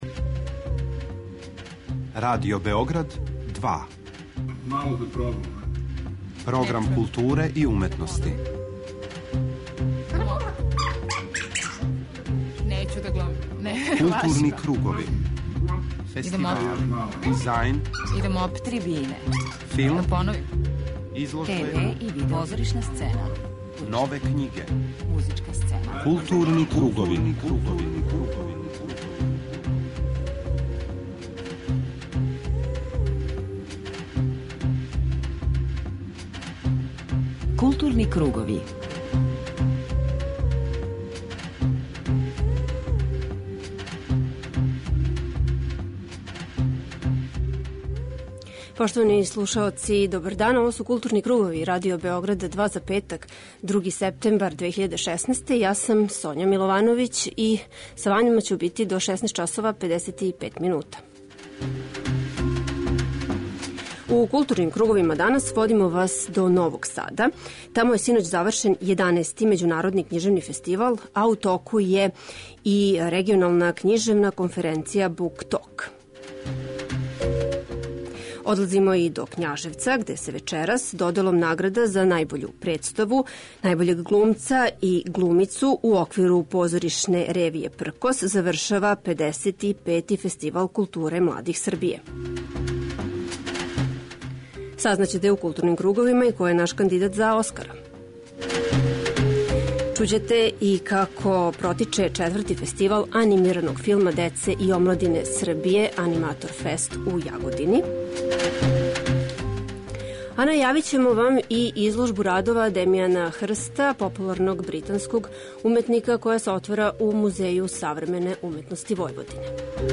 преузми : 41.15 MB Културни кругови Autor: Група аутора Централна културно-уметничка емисија Радио Београда 2.